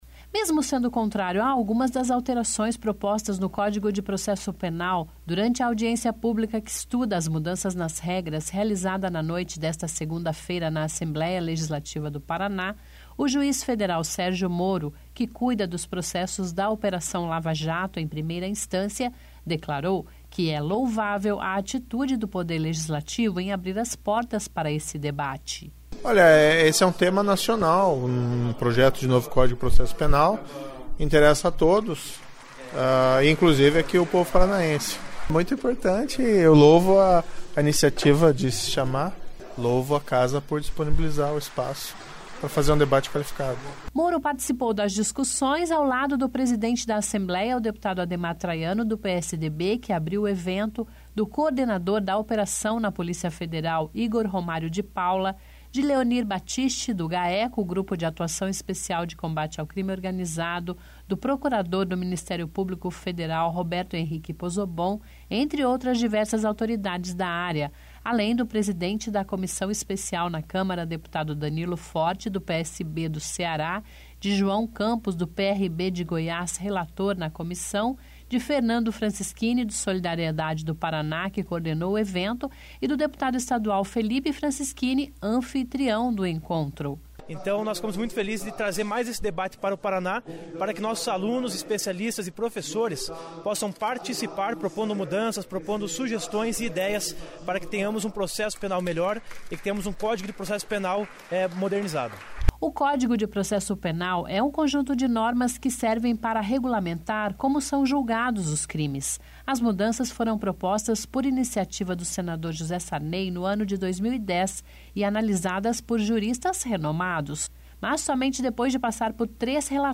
(Descrição do áudio) Mesmo sendo contrário a algumas das alterações propostas no Código de Processo Penal, durante a audiência pública que estuda as mudanças nas regras, realizada na noite desta segunda-feira (21) na Assembleia Legislativa do Paraná, o juiz federal Sérgio Moro, que cuida dos process...